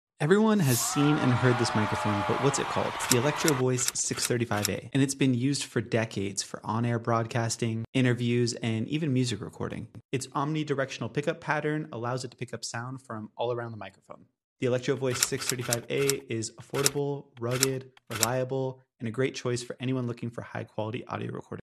The Electro-Voice 635a looks old-school but still sounds great